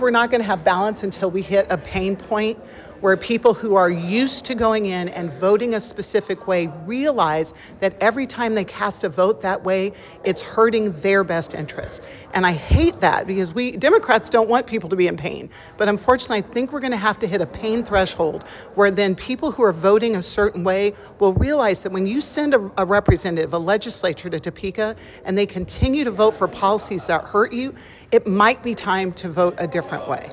Local, state Democrats hold town hall focusing on rural issues